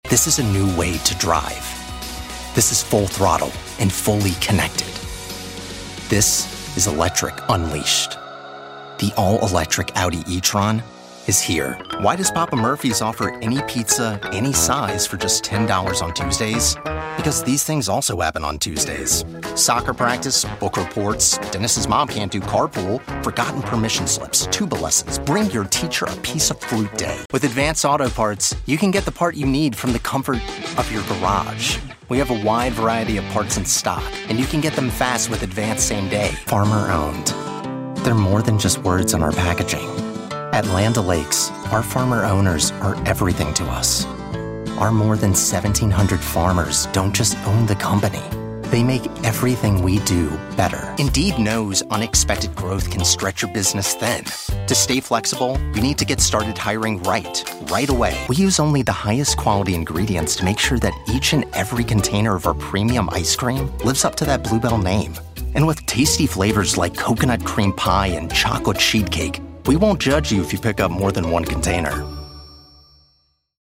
Demos
US (South), British, Irish (General)